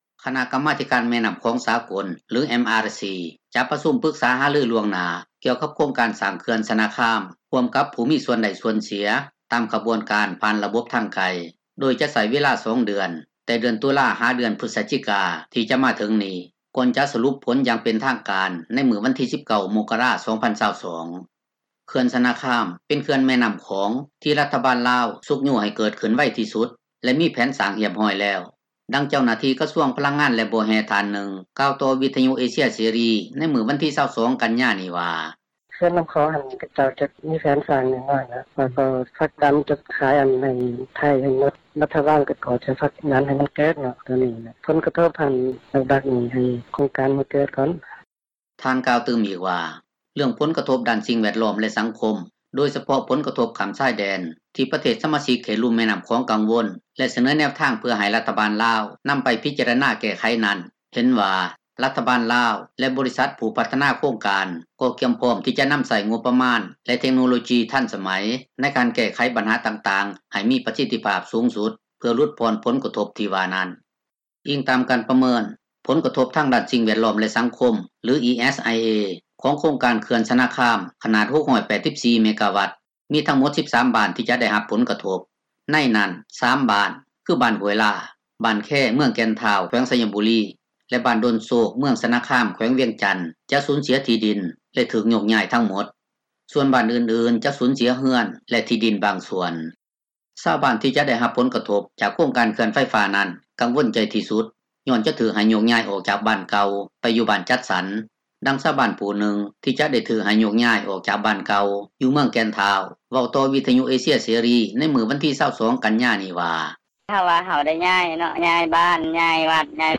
ປຶກສາຫາລືລ່ວງໜ້າ ໂຄງການ ເຂື່ອນຊະນະຄາມ — ຂ່າວລາວ ວິທຍຸເອເຊັຽເສຣີ ພາສາລາວ